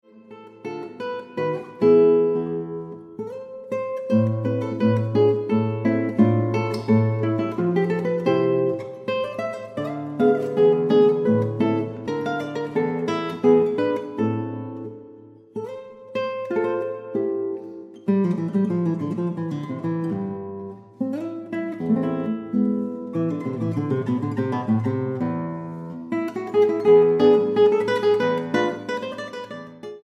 guitarra
gavota